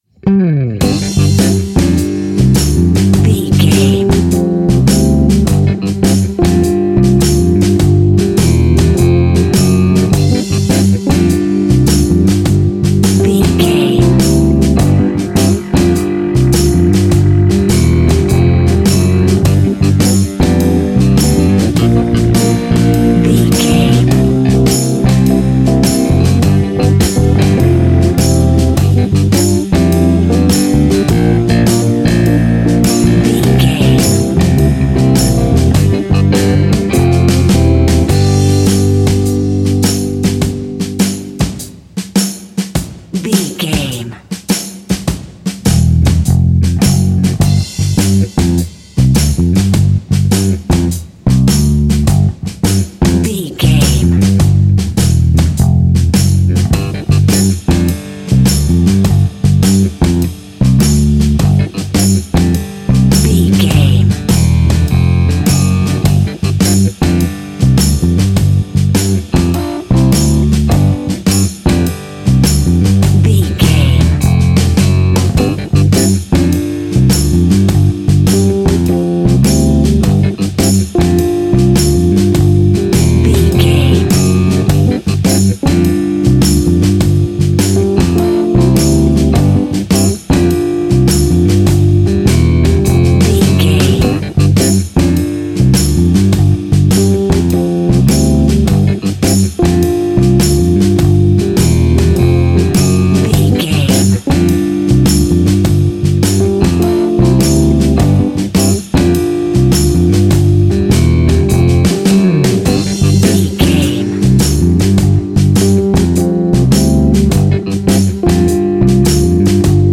Ionian/Major
uplifting
bass guitar
electric guitar
drums
saxophone